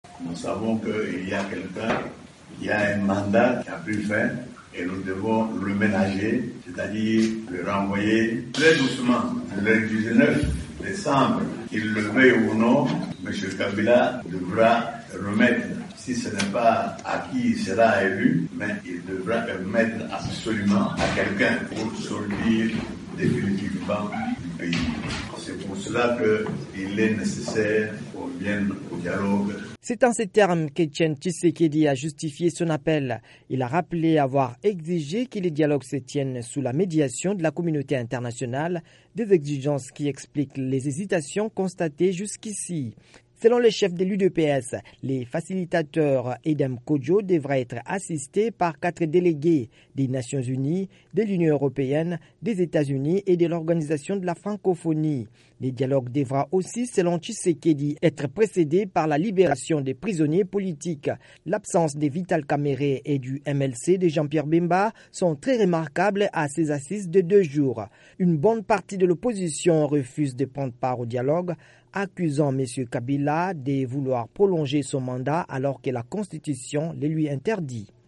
Reportage sur l'appel d'Etienne Tshisekedi pour le dialogue